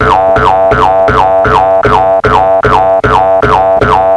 soundfx / cartoons
boingbng.wav